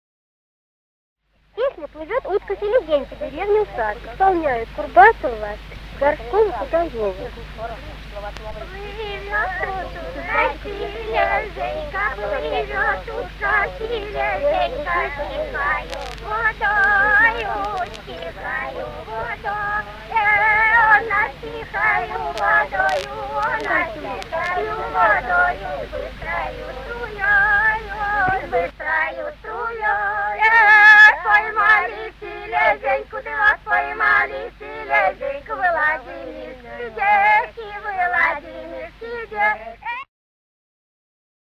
Русские народные песни Владимирской области 12. Плывёт утка-селезенька (хороводная) д. Усад Ляховского (с 1963 года Меленковского) района Владимирской области.